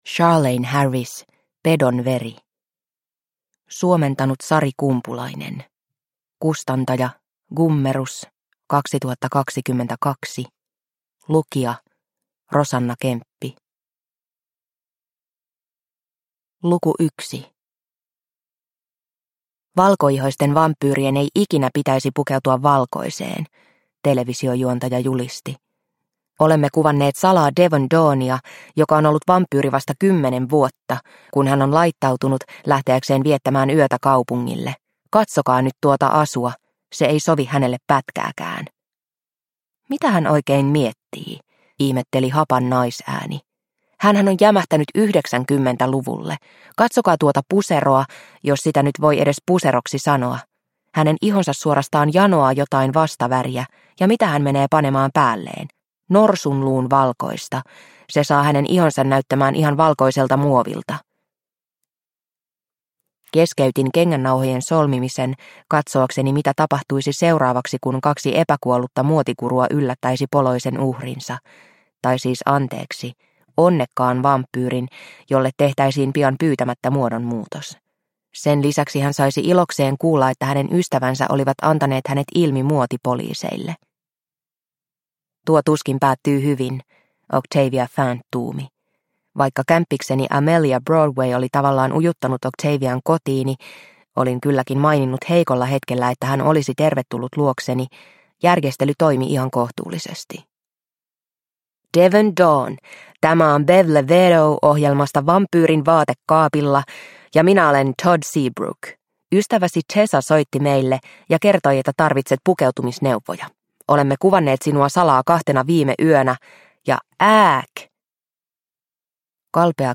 Pedon veri – Ljudbok – Laddas ner